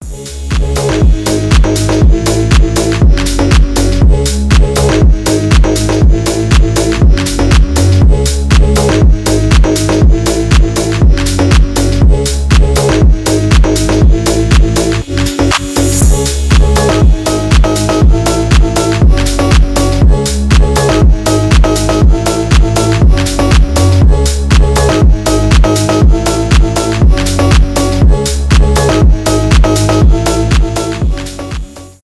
2022 » Без Слов » Фонк Скачать припев